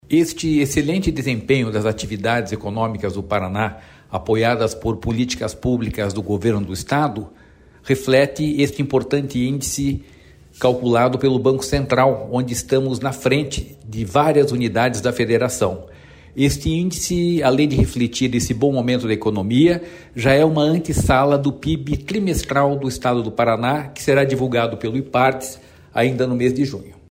Sonora do diretor-presidente do Ipardes, Jorge Callado, sobre a liderança nacional do Paraná no crescimento da atividade econômica no 1º trimestre